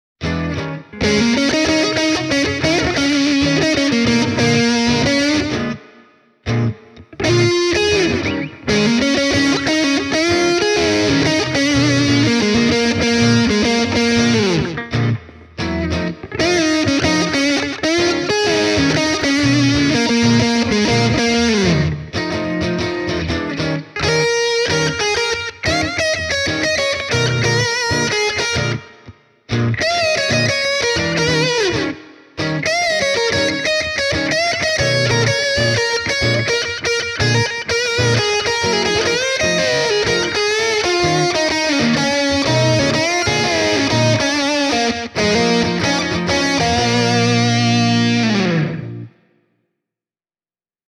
Demobiisissä soi kaksi komppikitaraa – Gibson Les Paul Junior (Channel One, oikea stereokanava) ja Fender Telecaster (Channel Two, vasemmalta) – sekä Epiphone Les Paul Standard -kitaralla (vintage-tyylisillä EMG HZ -mikrofoneilla) soitettu soolokitara (Channel Two, volume-säädin kello kolme):